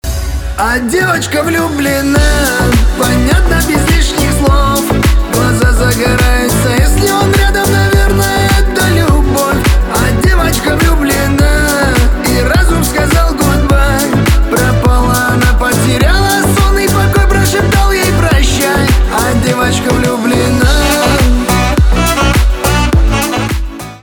кавказские
битовые , басы , романтические